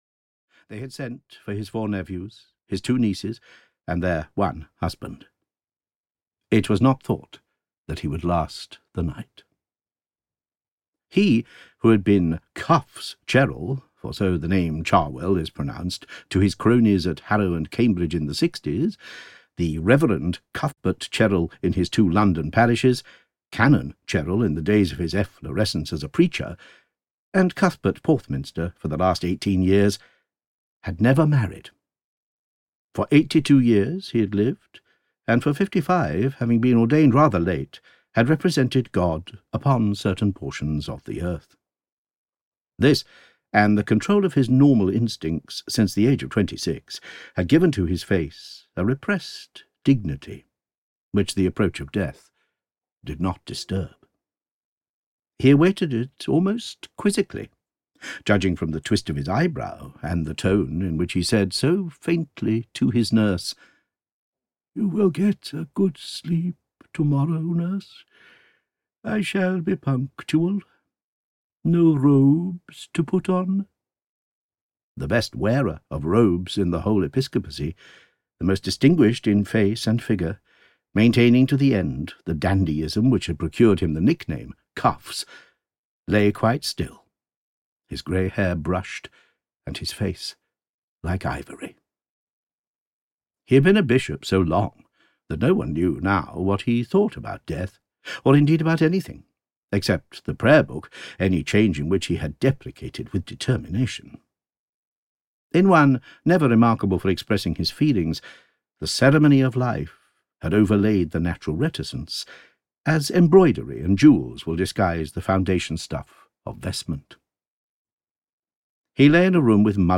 Audio knihaThe Forsyte Chronicles, Vol. 3: End of the Chapter (EN)
Ukázka z knihy